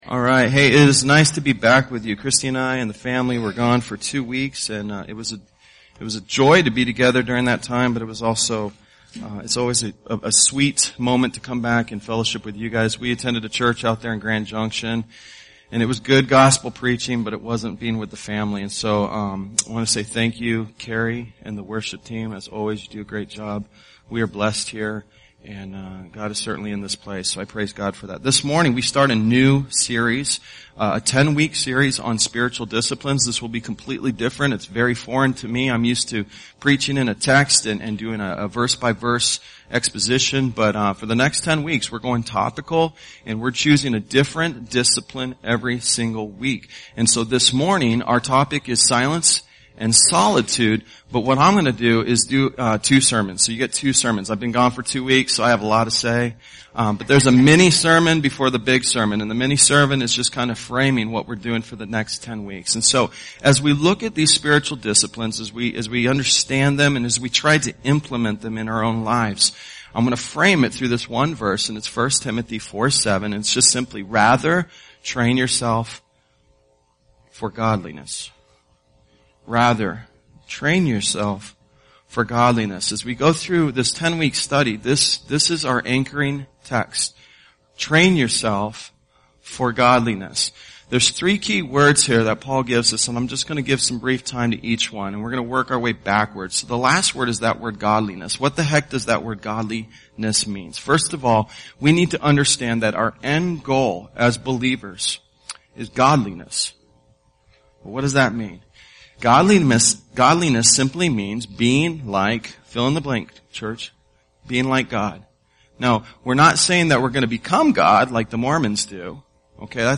Tagged with 10 Spiritual Disciplines , Sunday Sermons